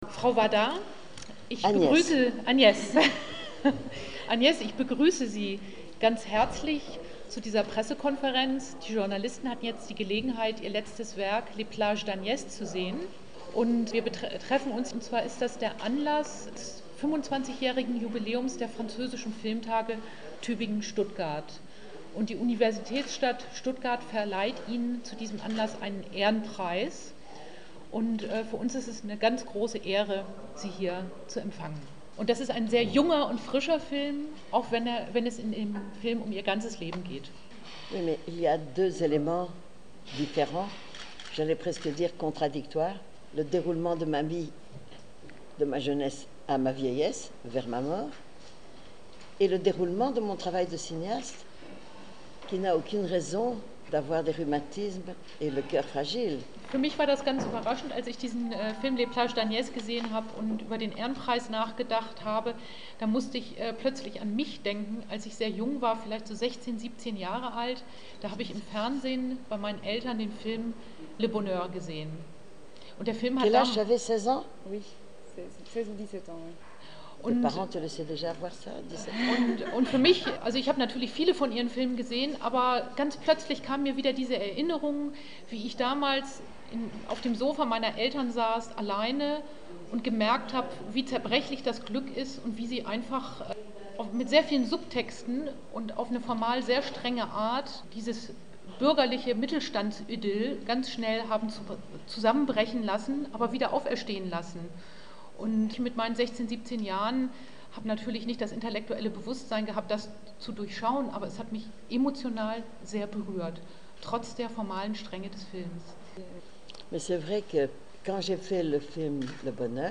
Aujourd’hui, Agnès Varda est venue à Stuttgart pour présenter son film „Les plages d’Agnès“ devant des journalistes dans le Kino am Bollwerk dans le cadre du > Festival International du Film Francophone qui se déroule actuellement à Tübingen et à Stuttgart.
Vous pouvez en écouter un extrait, sans les paroles de la traductrice – elle a très bien traduit – mais cet extrait présenté ne doit pas être trop long.